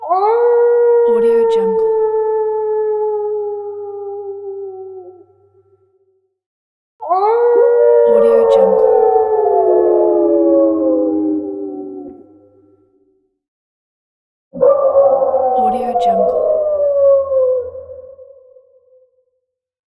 Night Wolf Howling Téléchargement d'Effet Sonore
Night Wolf Howling Bouton sonore